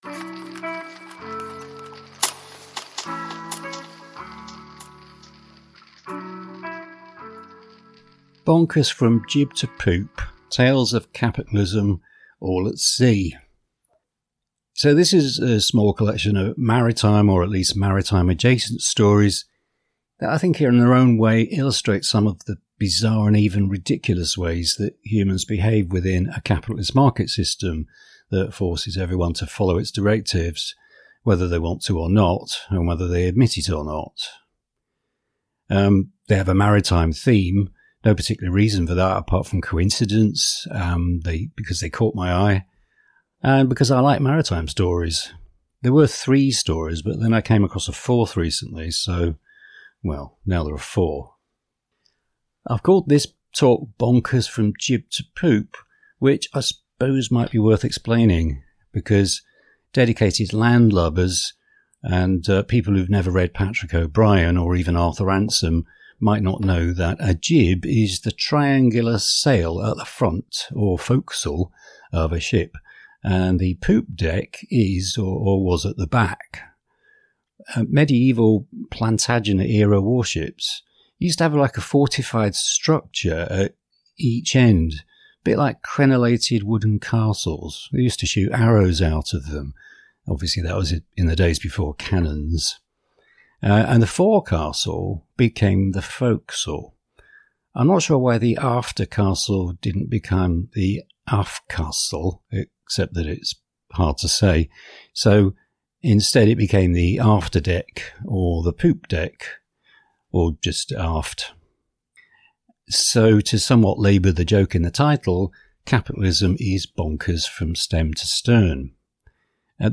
1 Bonkers From Jib to Poop – Tales of Capitalism All at Sea 35:49 Play Pause 40m ago 35:49 Play Pause Play later Play later Lists Like Liked 35:49 This is a talk that was given on 31 Oct 2025 on a general maritime theme. There's no particular point being made, just some observations on a number of vaguely related news stories from earlier in the year.